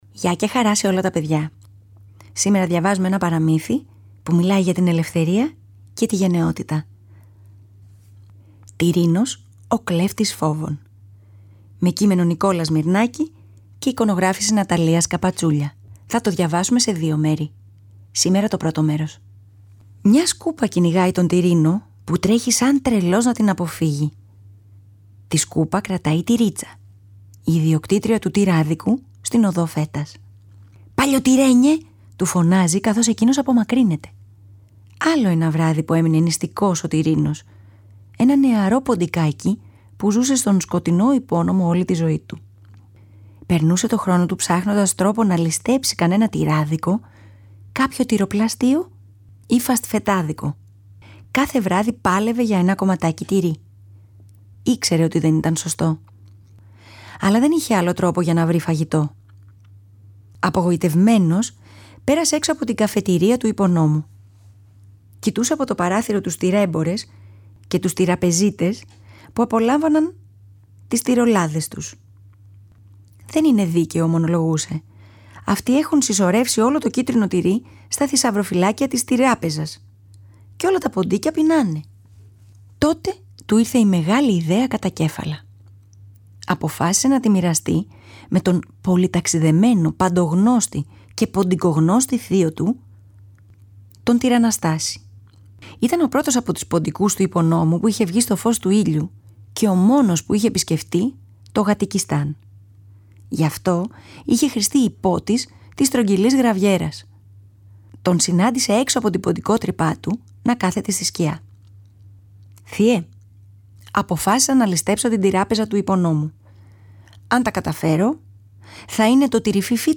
Θα το διαβάσουμε σε δύο μέρη.